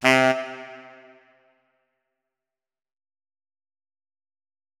saxophone